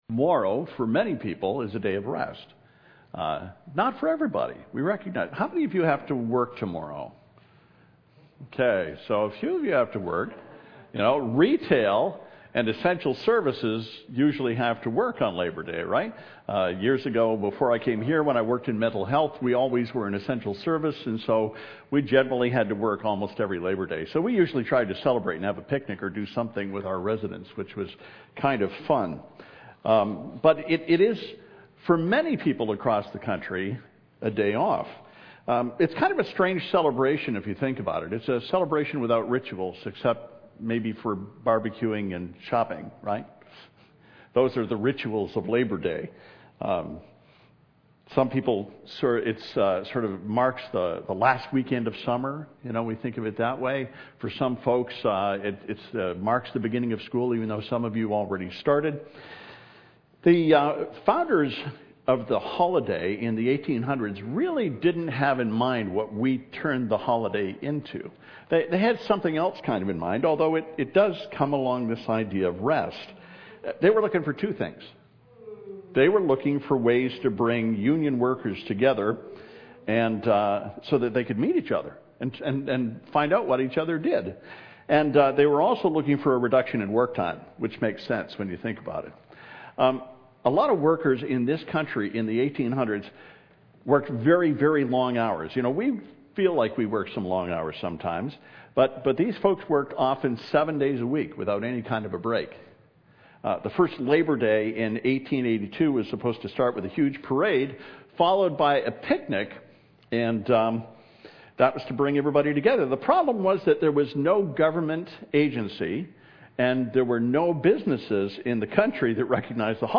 “Labor Intensive” Matthew 11:28 « FABIC Sermons
02-Sermon-Labor-1.mp3